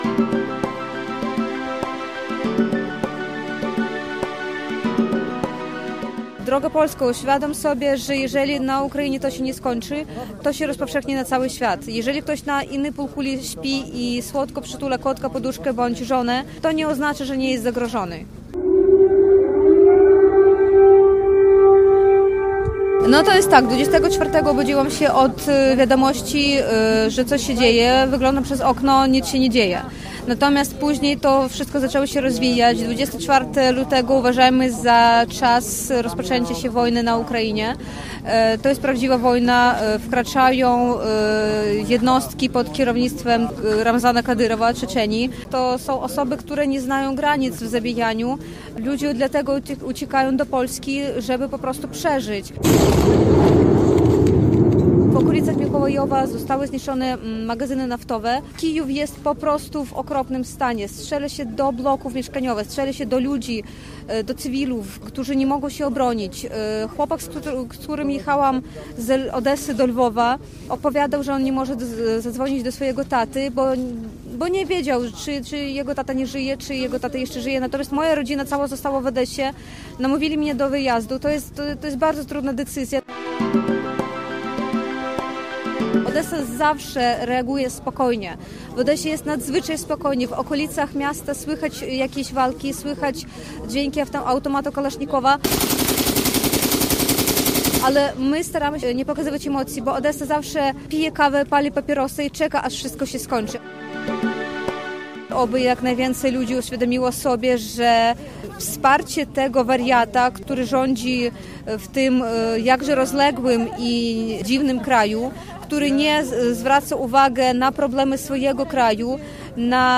Inwazja Rosji na Ukrainę. Reportaż "Zapach wojny" - Radio Łódź